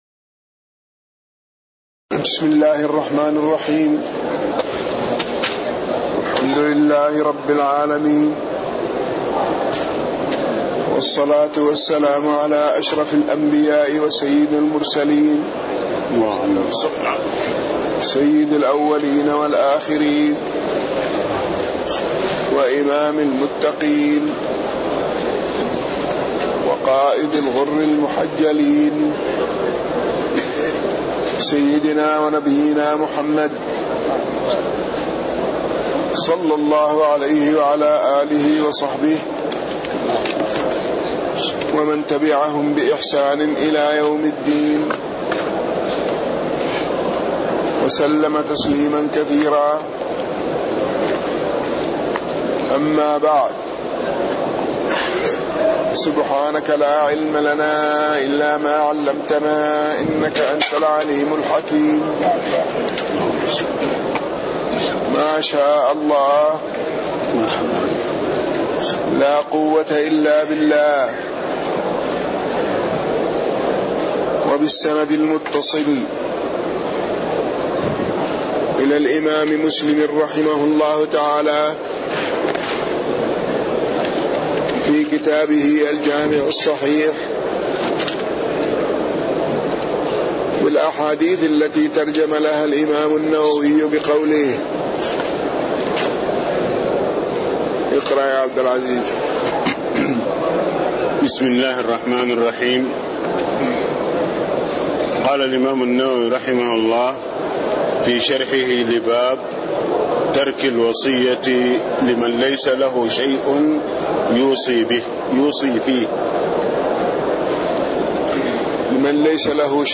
تاريخ النشر ٢٥ ربيع الثاني ١٤٣٥ هـ المكان: المسجد النبوي الشيخ